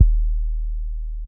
Kick SwaggedOut 10.wav